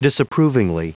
Prononciation du mot disapprovingly en anglais (fichier audio)
Prononciation du mot : disapprovingly